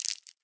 糖果包装纸 " 糖果包装纸皱纹短B
描述：用手指把塑料糖果的包装纸简单地捏皱了一下。
Tag: 糖果 起皱 包装